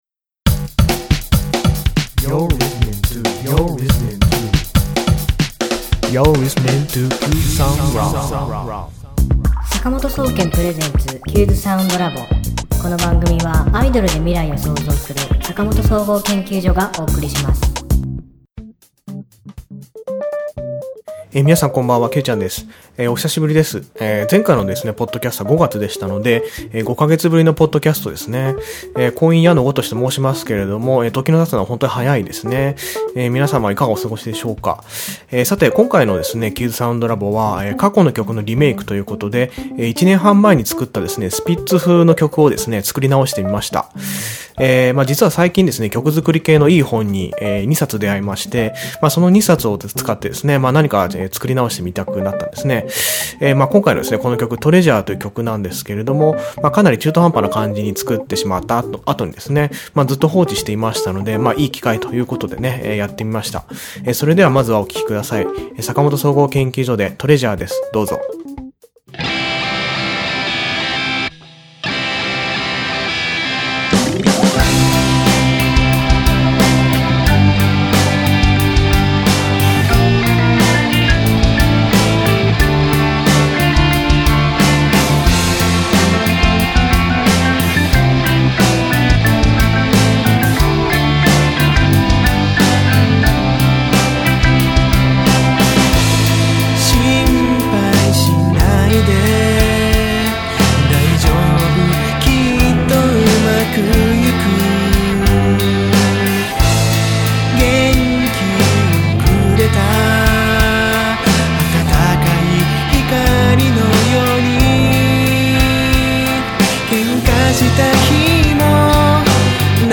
ギター